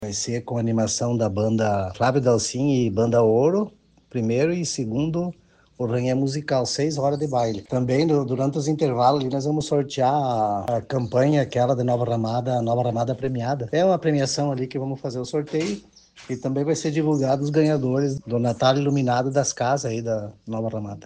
O município de Nova Ramada vai realizar, hoje, programação de aniversário. À noite, haverá baile no clube de Pinhal, com acesso gratuito, conforme explica o prefeito, Alzevir de Marchi.